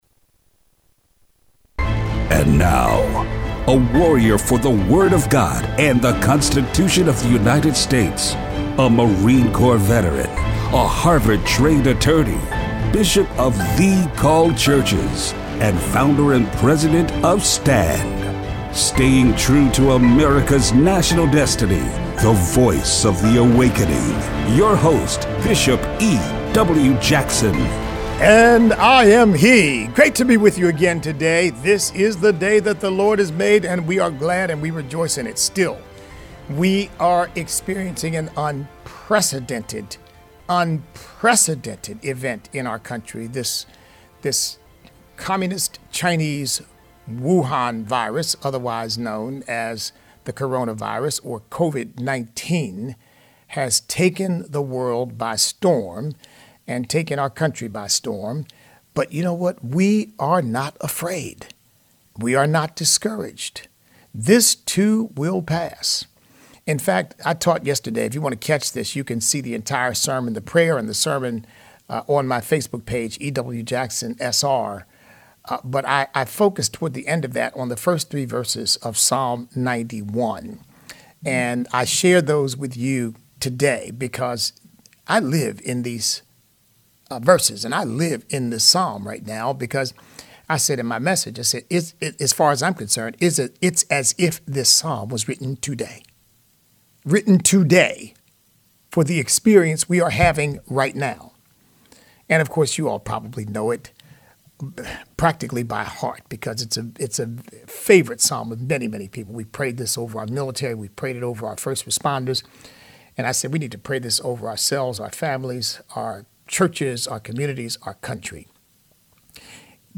Show Notes Are science and Christianity compatible? Coronavirus and the Constitution. Listener call-in.